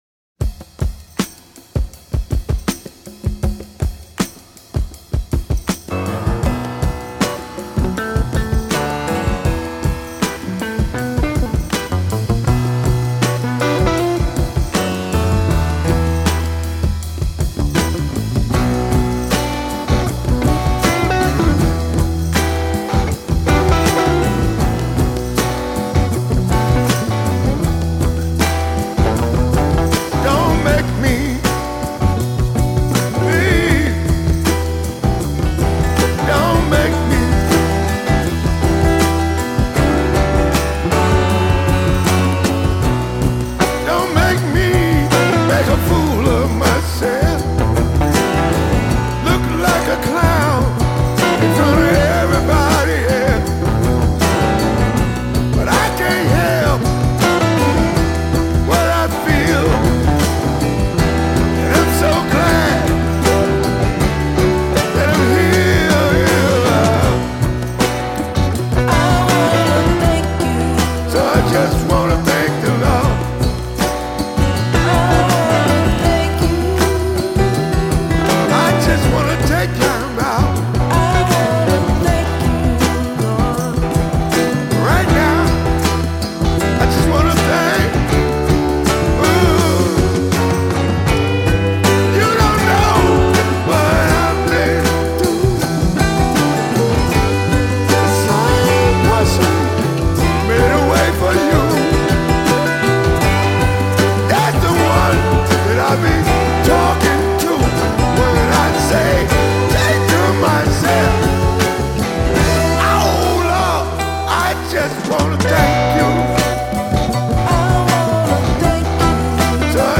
соулу, R&B, госпелу и блюзу